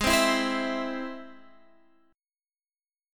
G#+ Chord
Listen to G#+ strummed